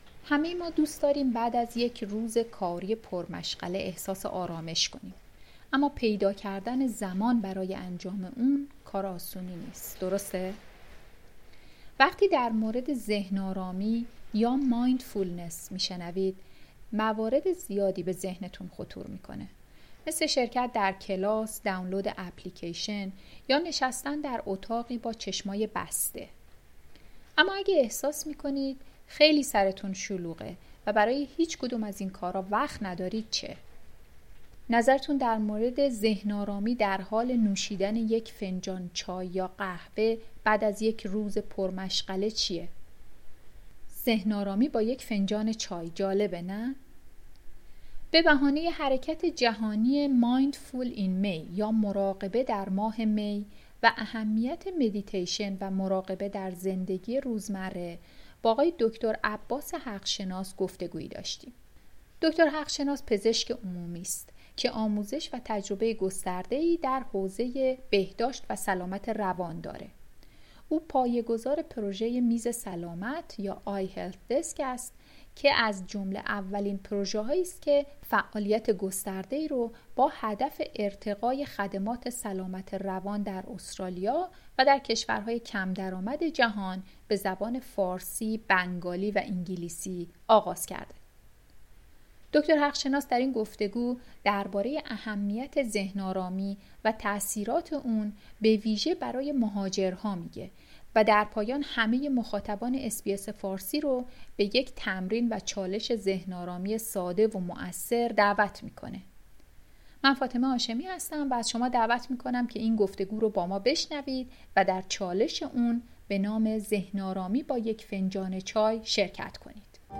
گفتگو: ذهن‌آرامی با یک فنجان چای